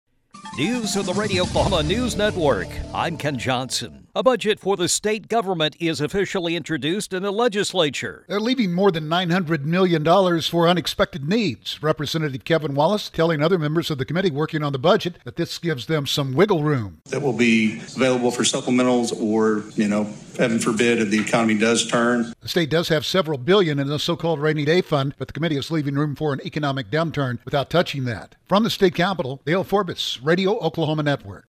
As more than 900 million dollars will be saved for unexpected needs, Representative Kevin Wallace told other members of the committee working on the budget that this will give them some “wiggle-room.”